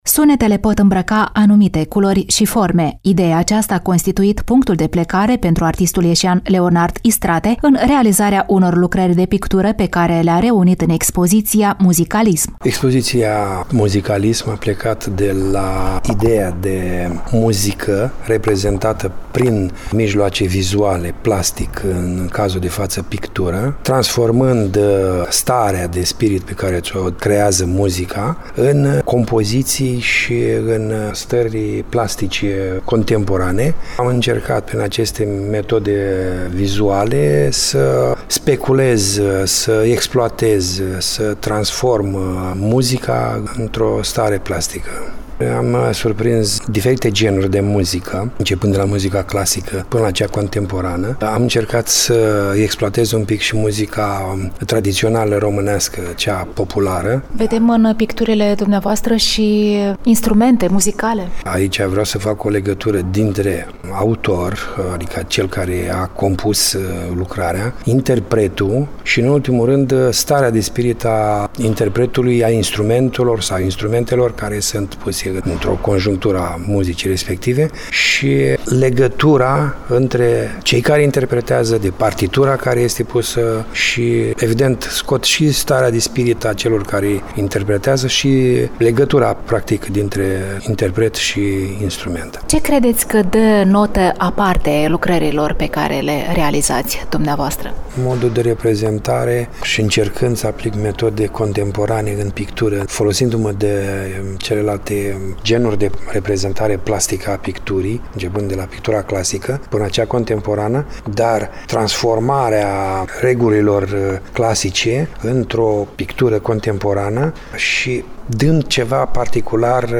Reportaj Cultural